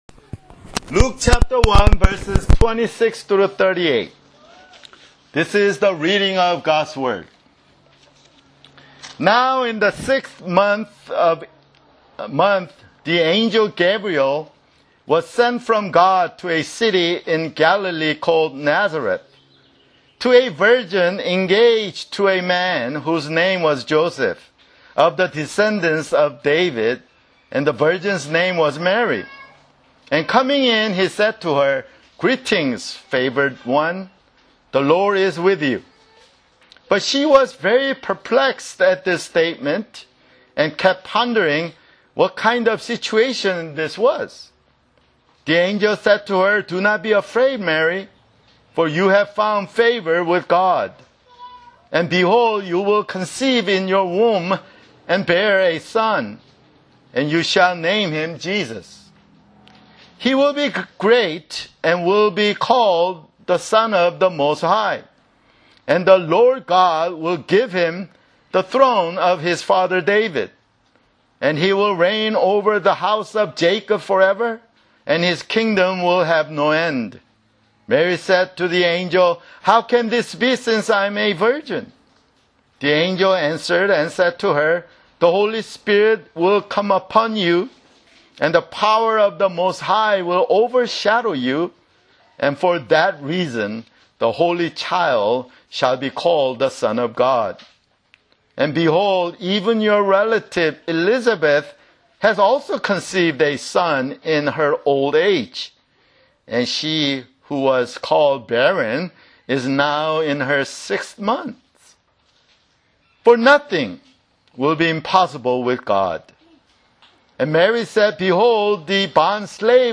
[Sermon] Luke (5)